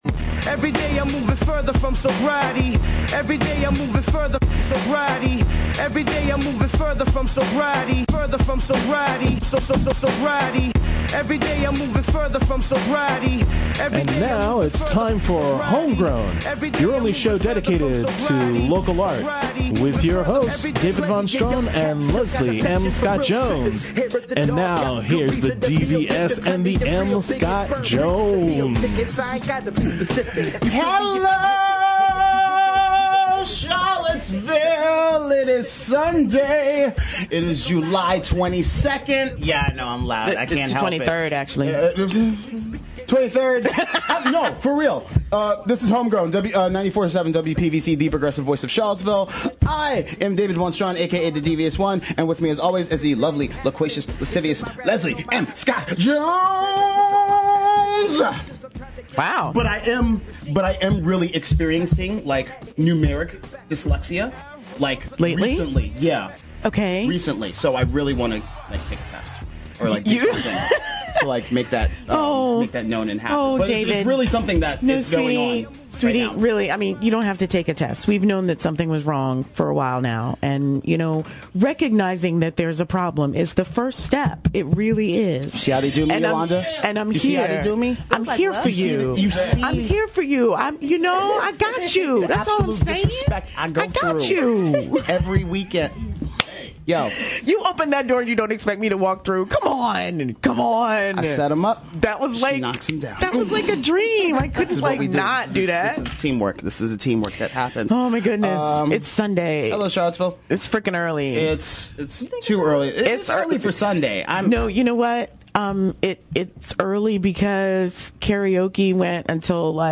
Get ready for a conversation about artistic and family intimacy.